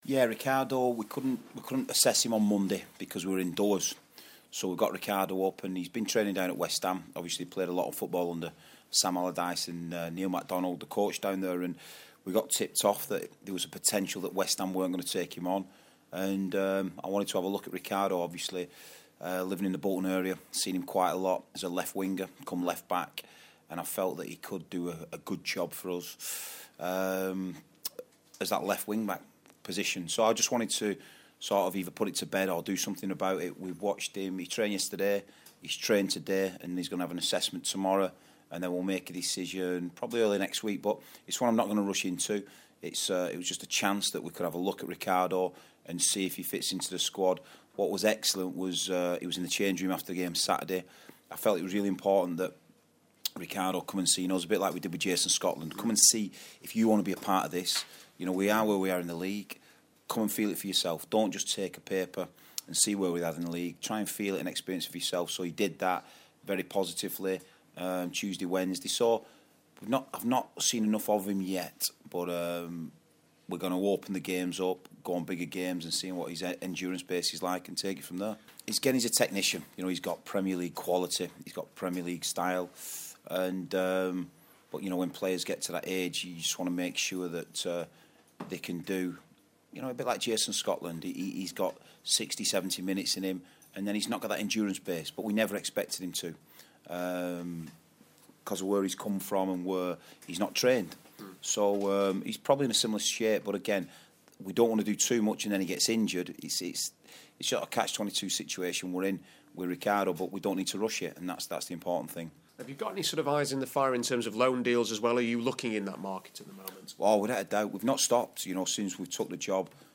The Reds boss speaks to BBC Radio Sheffield